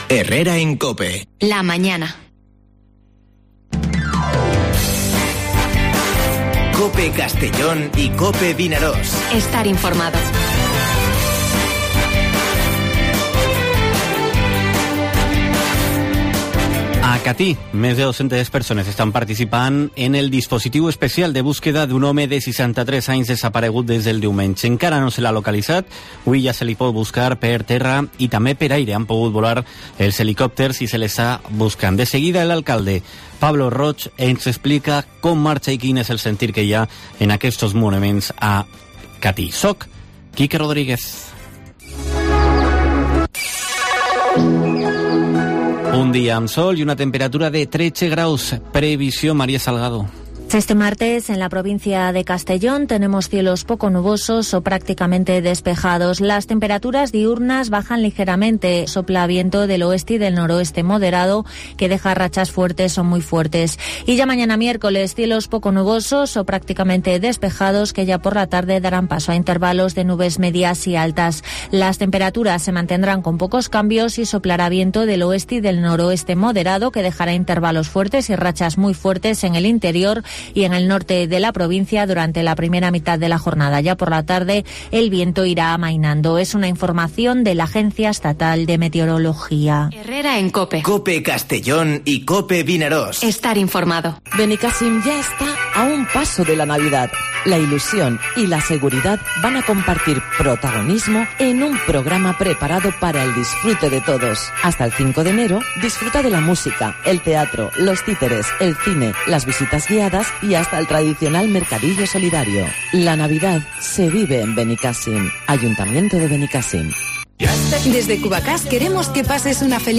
Dos historias que debes conocer este martes: Búsqueda del hombre desaparecido desde hace 48 horas con el alcalde de Catí, Pablo Roig; y retos para 2021 del Puerto de Castellón con el presidente de la Autoridad Portuaria, Rafa Simó.